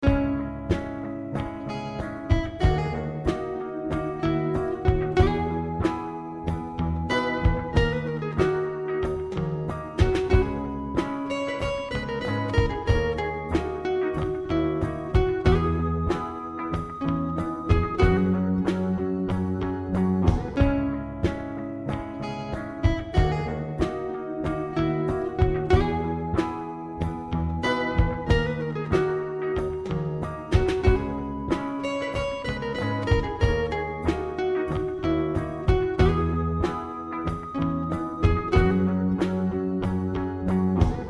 Band バンド音源バンド音源バンド音源
ループ可能